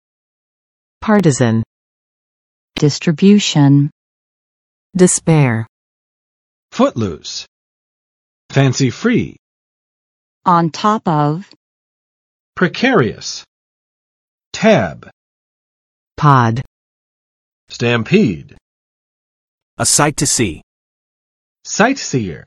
[ˋpɑrtəzn] adj. 由一个党派组成（或控制）的